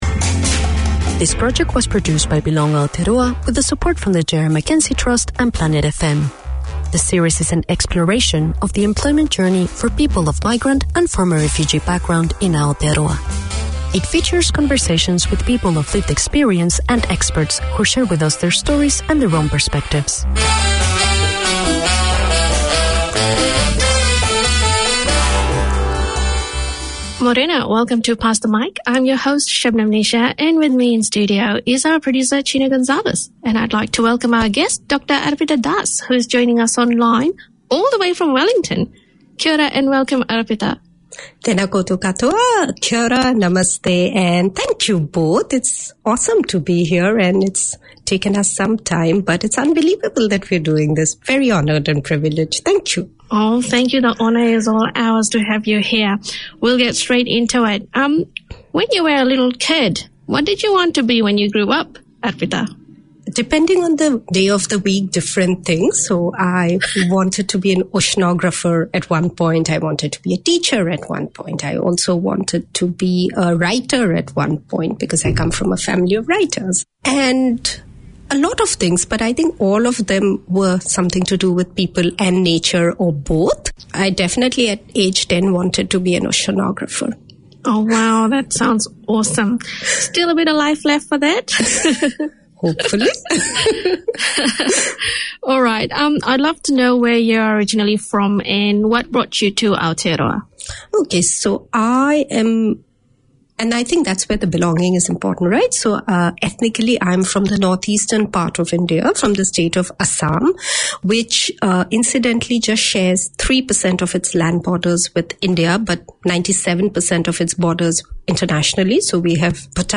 Pasifika Wire Live is a talanoa/chat show featuring people and topics of interest to Pasifika and the wider community.
Pasifika Wire 4:50pm SATURDAY Community magazine Language: English Pasifika Wire Live is a talanoa/chat show featuring people and topics of interest to Pasifika and the wider community.